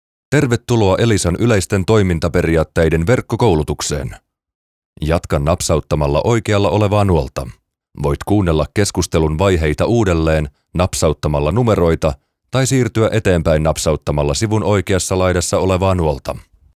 Deep and masculine Finnish voice over talent.
Versatile voice from ultra low to high midrange.
Sprechprobe: eLearning (Muttersprache):